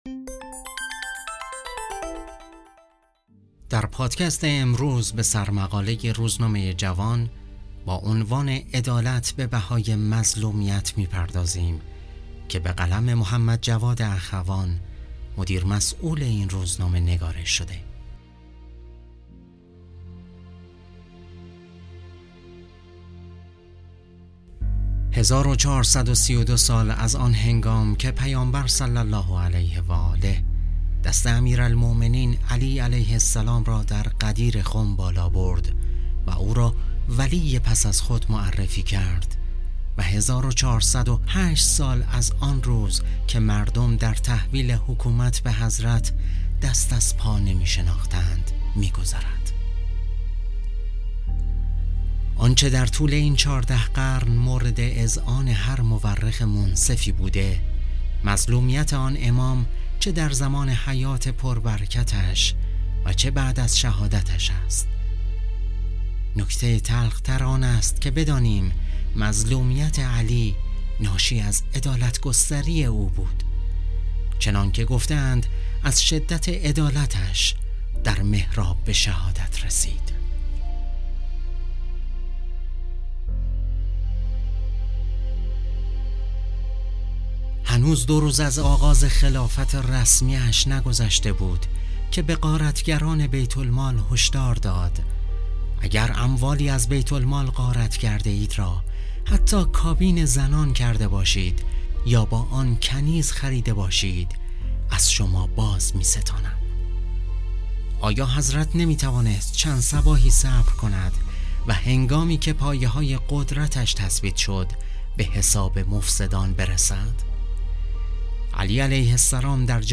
گوینده پادکست: